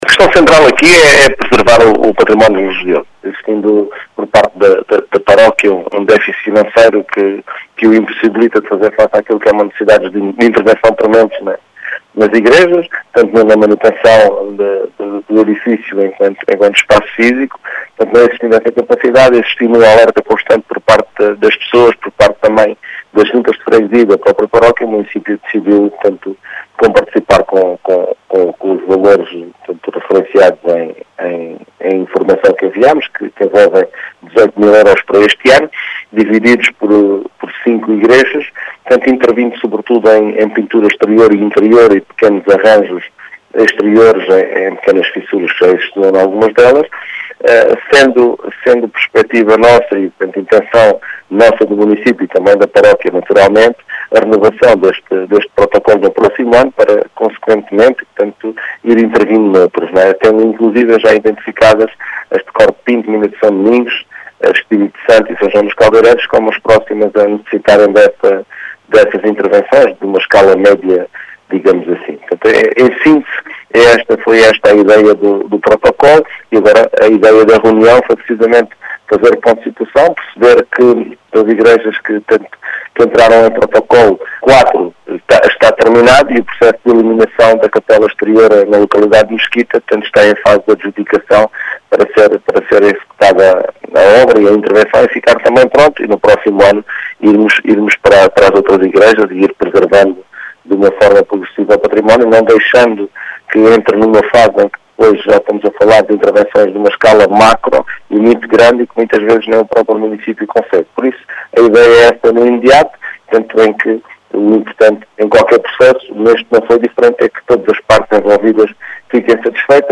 As explicações são deixadas pelo vice-presidente da Câmara de Mértola, Mário Tomé, que realça a importância da “preservação do património religioso”, naquele concelho.